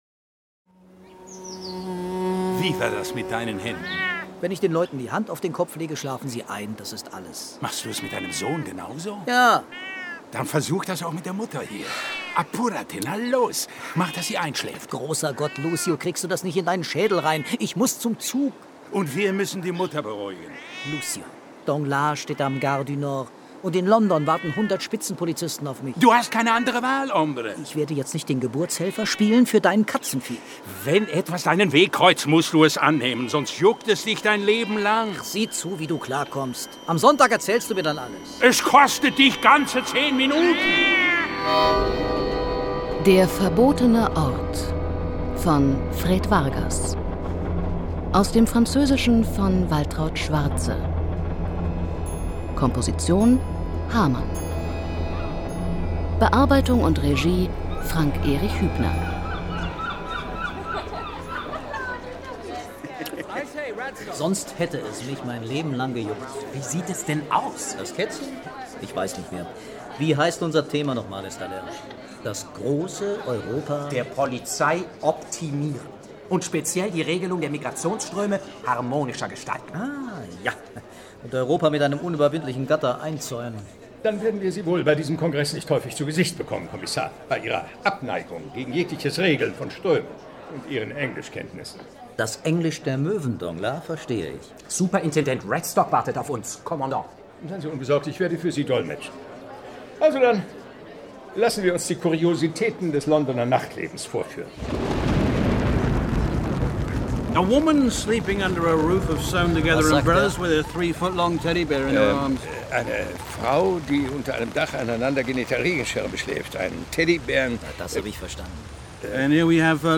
Hörbuch: Corpus Delicti.
Corpus Delicti. Ein Prozess Lesung